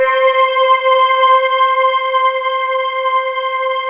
Index of /90_sSampleCDs/USB Soundscan vol.28 - Choir Acoustic & Synth [AKAI] 1CD/Partition D/22-RESOVOXAR